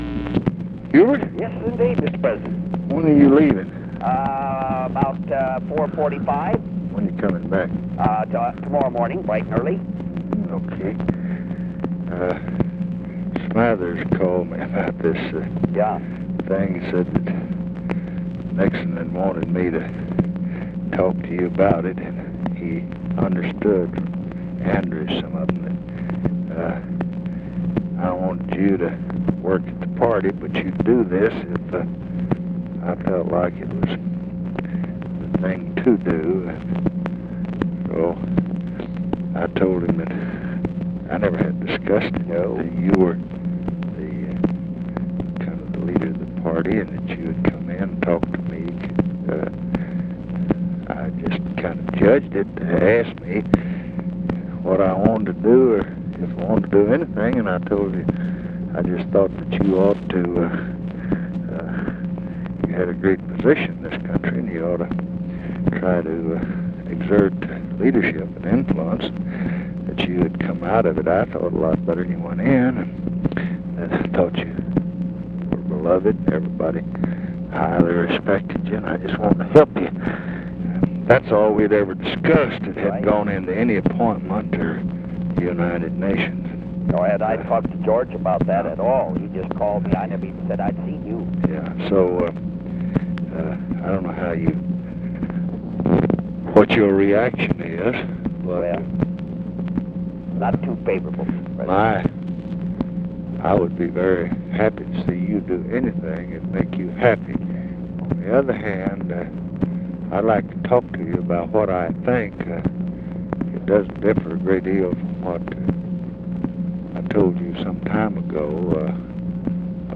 Conversation with HUBERT HUMPHREY, November 21, 1968
Secret White House Tapes